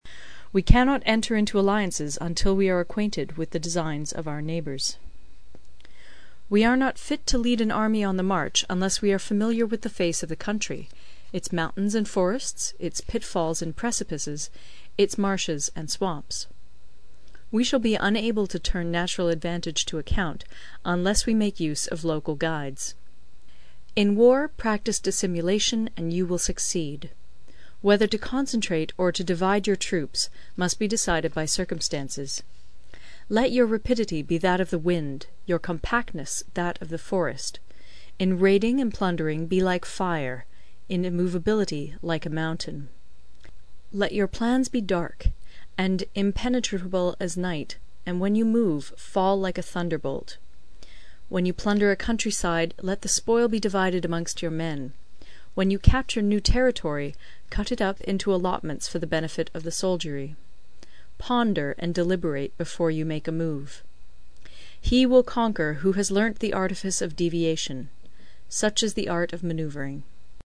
有声读物《孙子兵法》第41期:第七章 军争(3) 听力文件下载—在线英语听力室